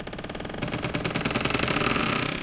door_A_creak_10.wav